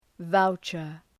Shkrimi fonetik {‘vaʋtʃər}